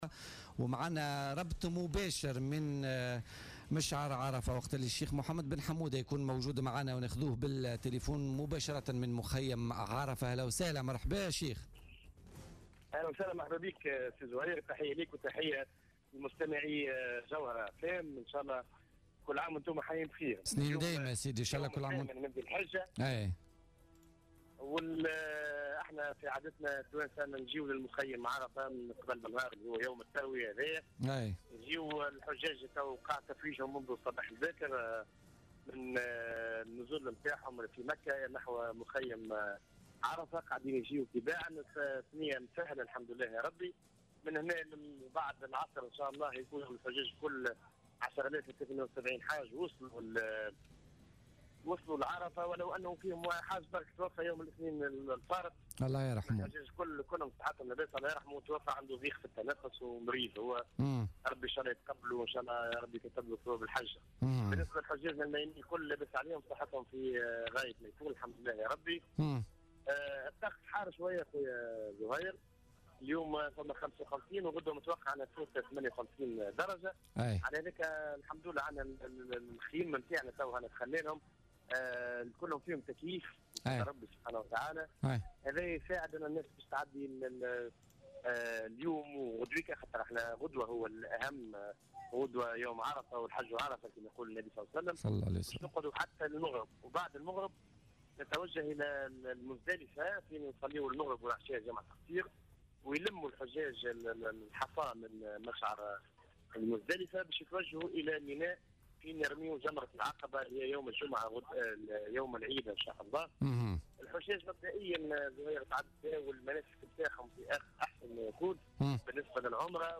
في مداخلة له اليوم الأربعاء في بولتيكا من مخيم الحجيج التونسيين بعرفة أن الحجاج وقع ترحيلهم من نزلهم في مكة تباعا نحو مخيماتهم في عرفة في يوم التروية.